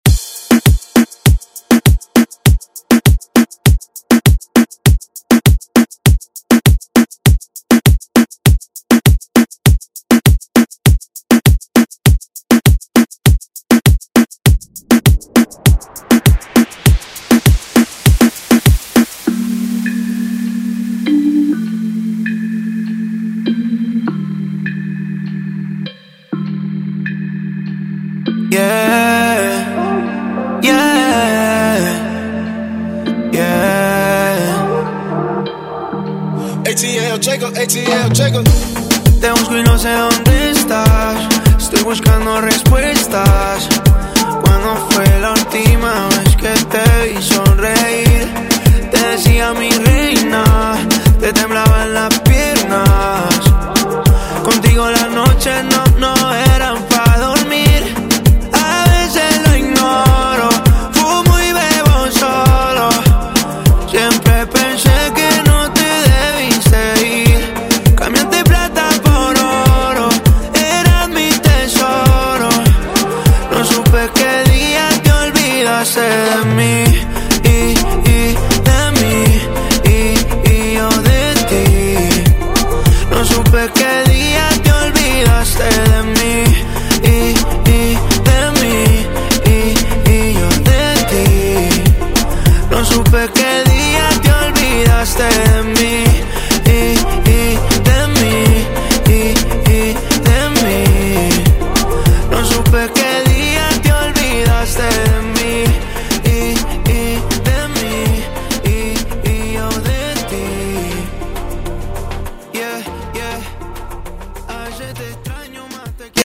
Genre: 80's
Clean BPM: 112 Time